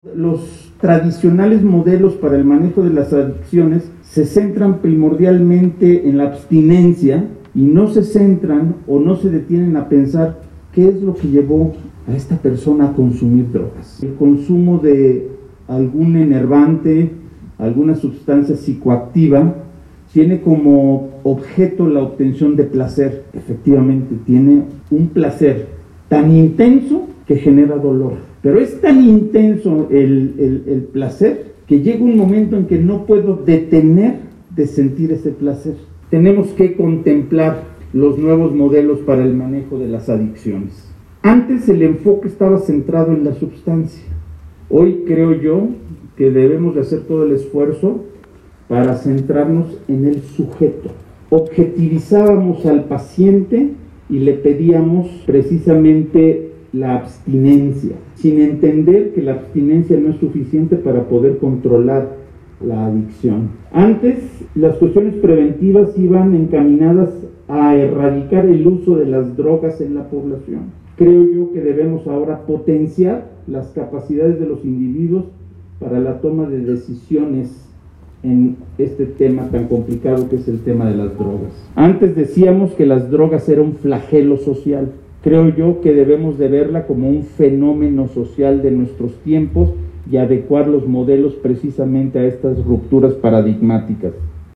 Secretario-de-Salud-de-Hidalgo-Alejandro-Efrain-Benitez-Herrera-2.mp3